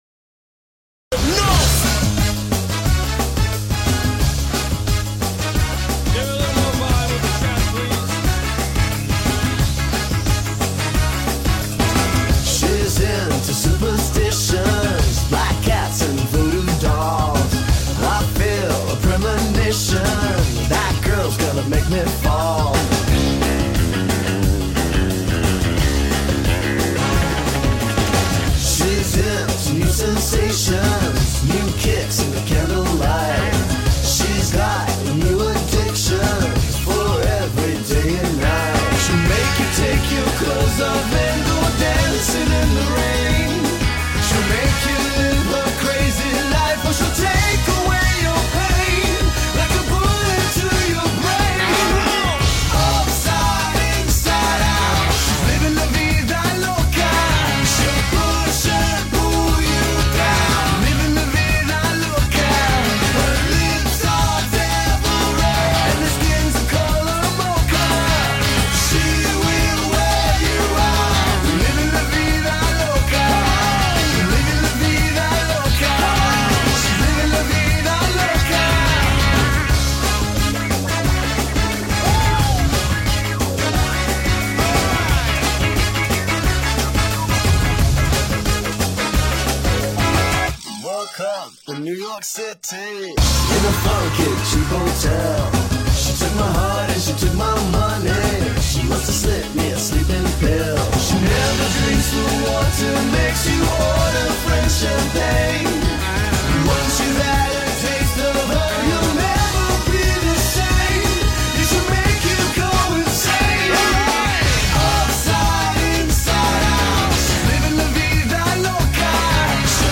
Latin Pop, Pop, Dance-Pop